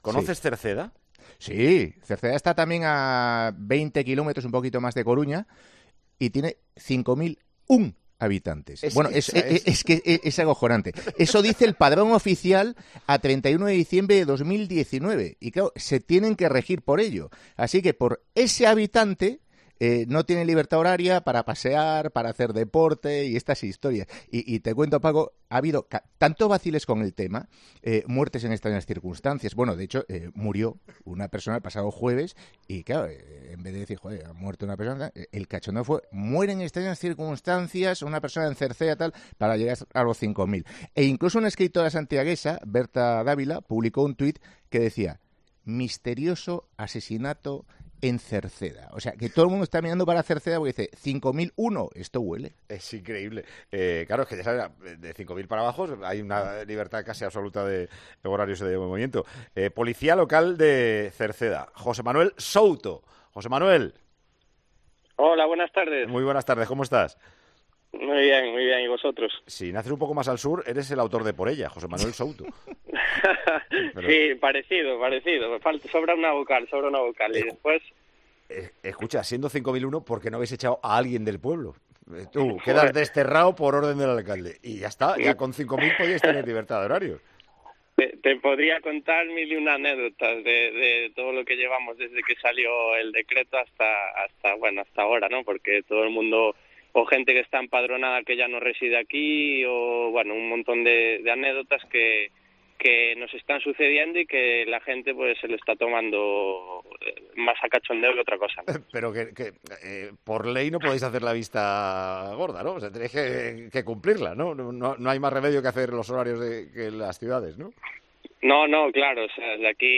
La localidad gallega censó su población en 5.001 habitantes, por lo que sus vecinos no podrán salir a la calle sin franja horaria. Nos atiende un Policía de la localidad.